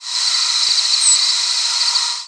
Field Sparrow nocturnal
hypothetical Field Sparrow nocturnal flight call